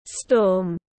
Storm /stɔːm/